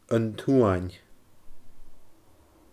An t-Suain /əN tuəNʲ/